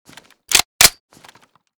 m14_unjam.ogg